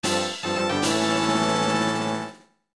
item_get.mp3